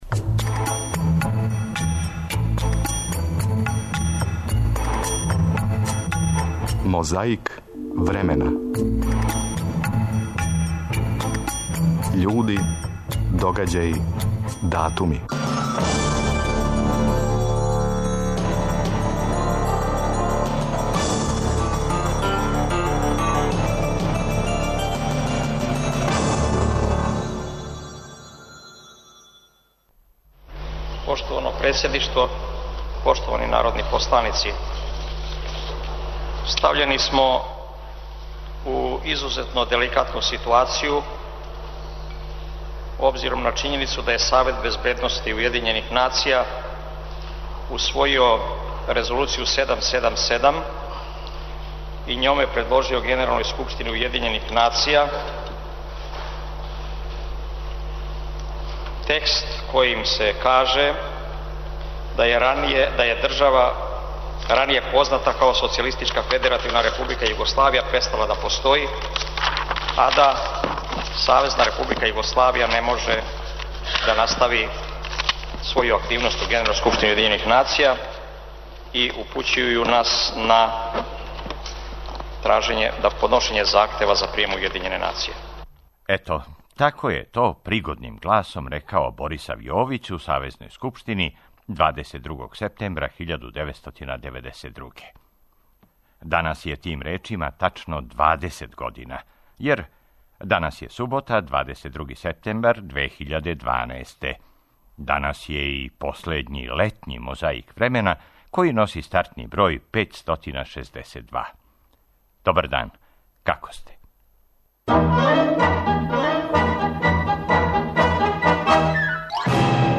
Микрофони раде, магнетофони и камере снимају.
Плус јесење лишће, пропланци, песме...